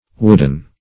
Wooden \Wood"en\, a.